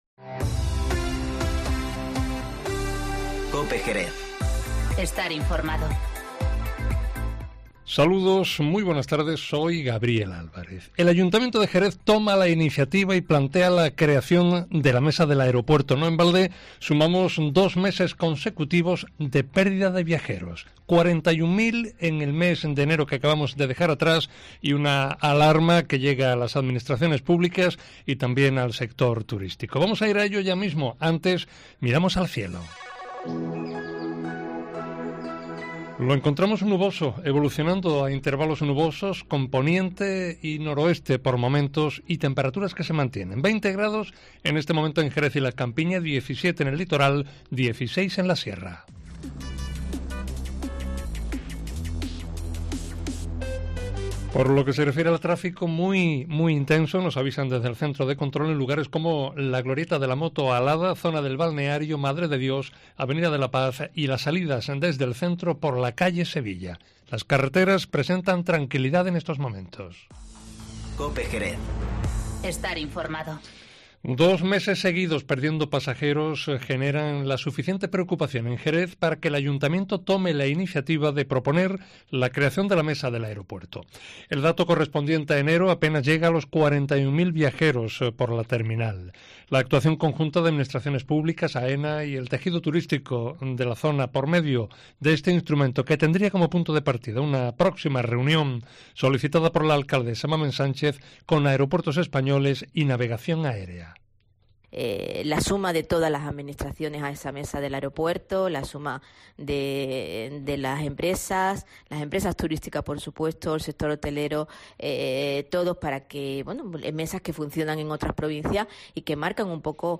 Informativo Mediodía COPE en Jerez 13-02-20